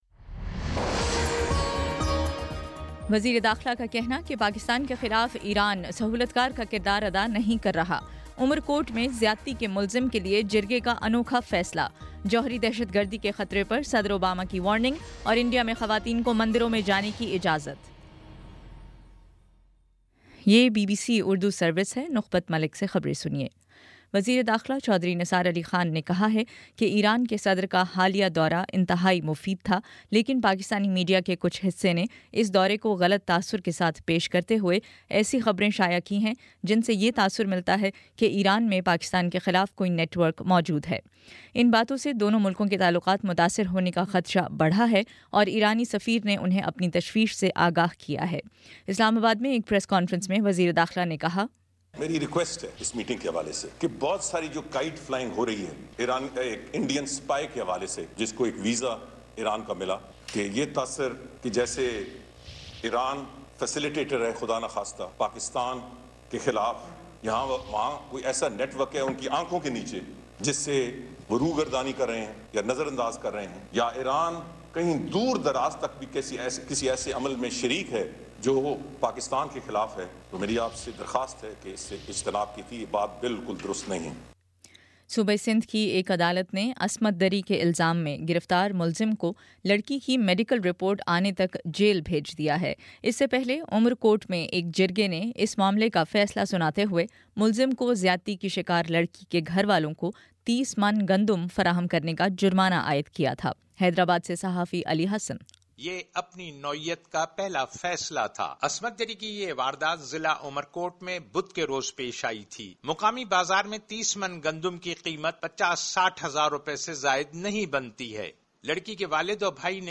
اپریل 02: شام سات بجے کا نیوز بُلیٹن